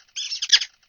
sounds / monsters / rat / attack_2.ogg
attack_2.ogg